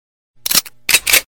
Ametralladora Carga
Tags: botones programa radio fatality la nave